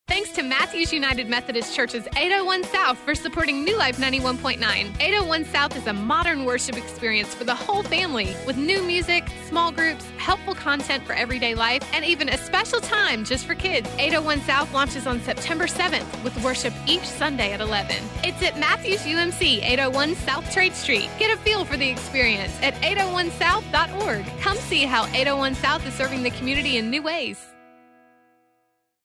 Radio Spot for 91.9 New Life: